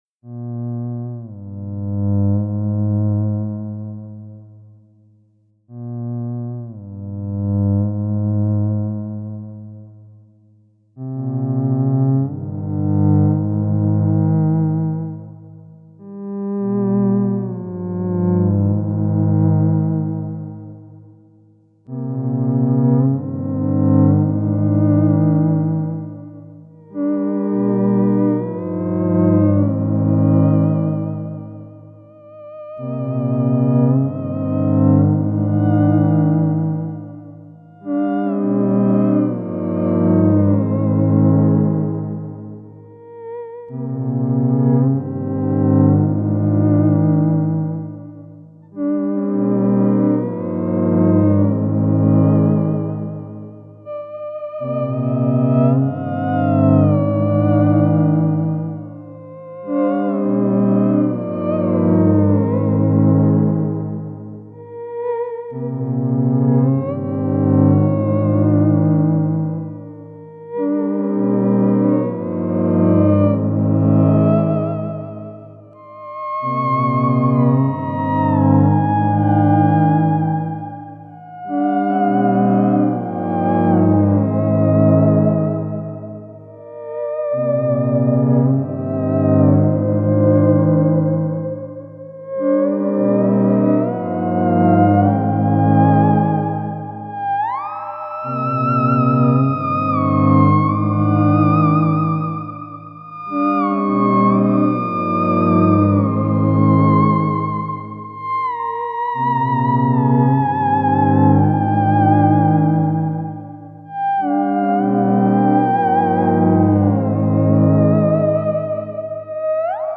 (SLOWED DOWN)